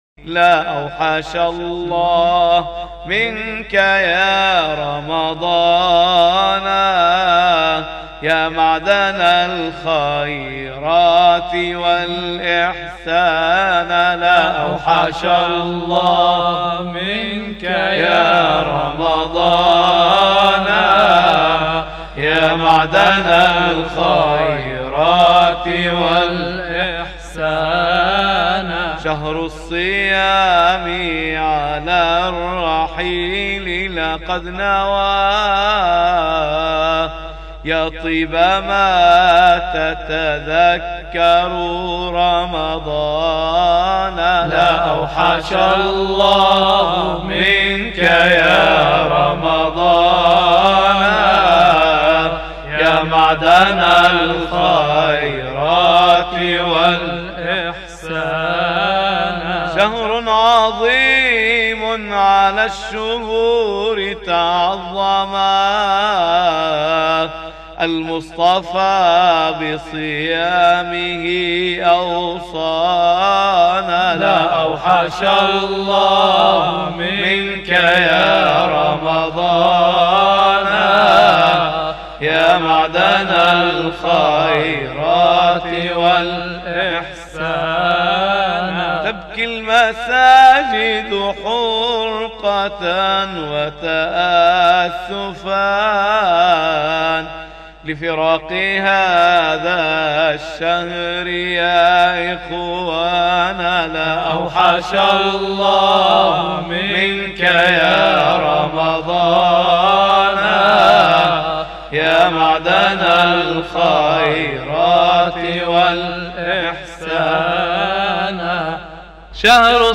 ابتهال لا أوحش الله منك يا رمضان
مسجد الحاج نمر-نابلس